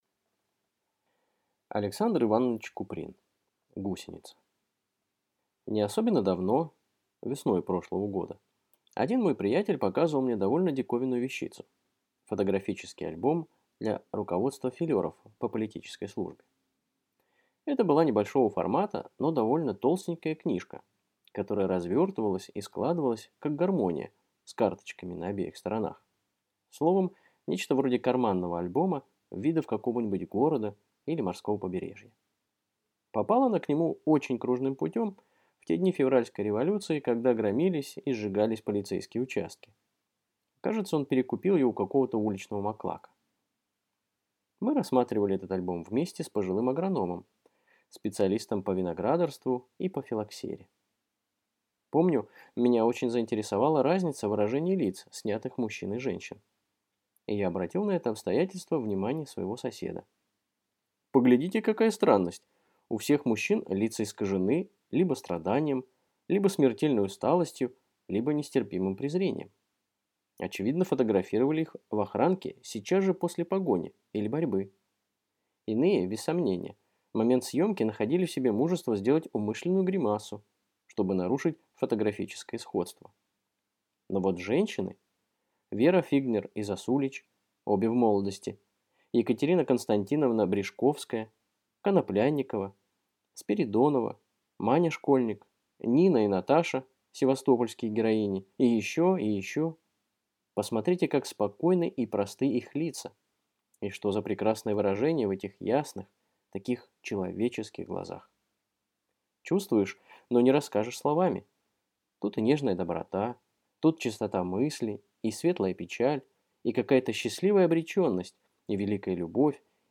Аудиокнига Гусеница | Библиотека аудиокниг